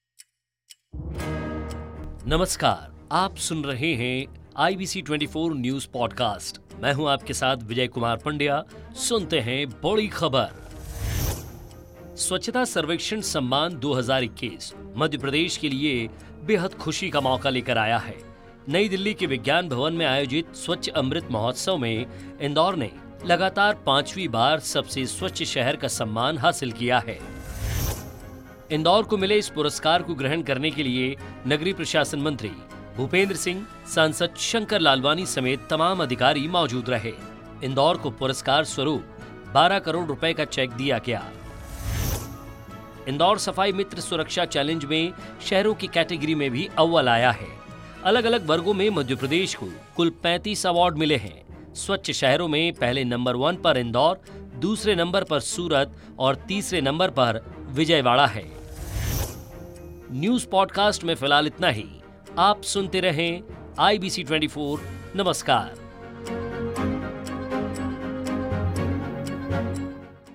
Today big news
आज की बड़ी खबर